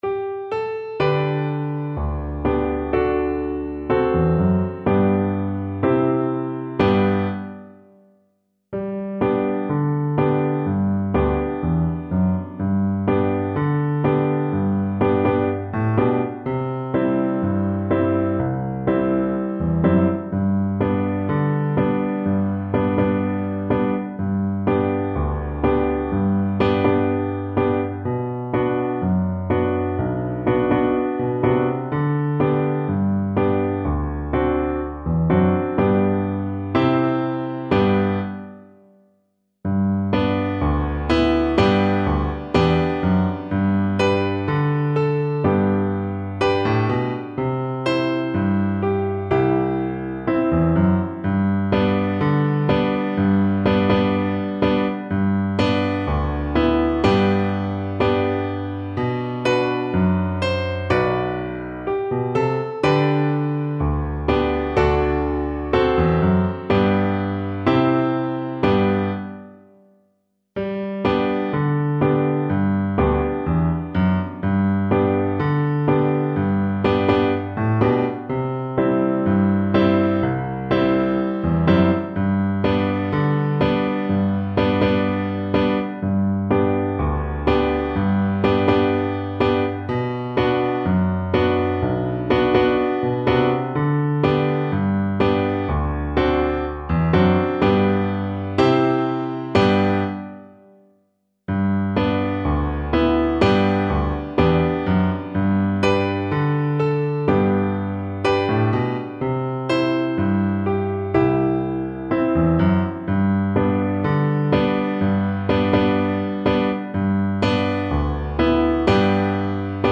Free Sheet music for Soprano (Descant) Recorder
Recorder
Traditional Music of unknown author.
G major (Sounding Pitch) (View more G major Music for Recorder )
4/4 (View more 4/4 Music)
Moderato =c.90
D6-D7
Traditional (View more Traditional Recorder Music)